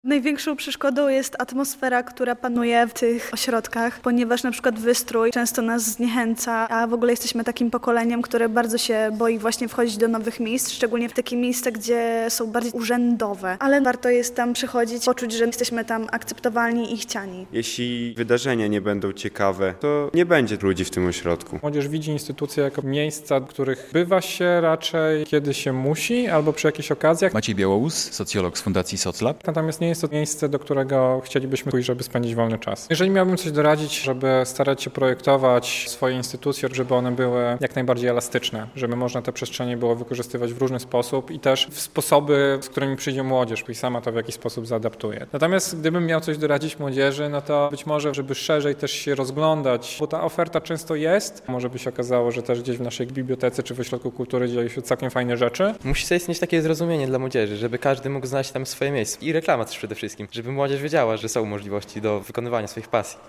Konferencja w Podlaskim Instytucie Kultury w Białymstoku - relacja